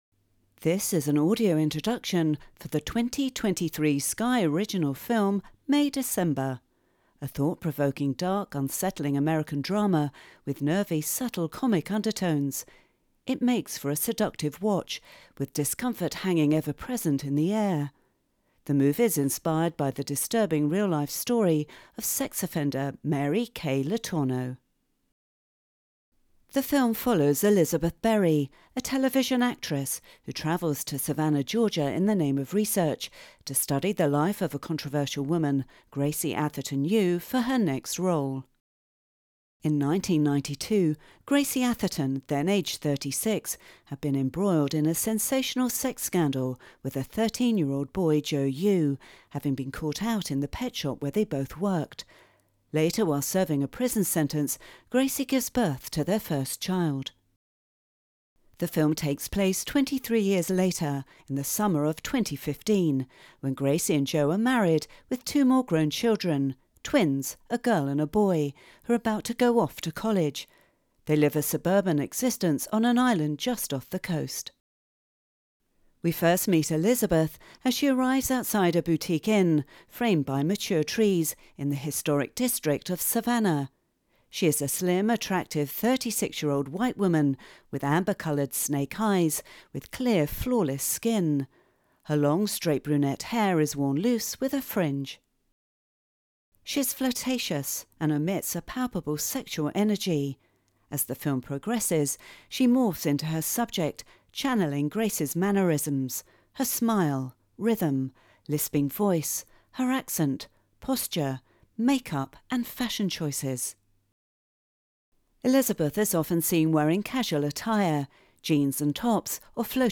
AD_Introduction_May_December.wav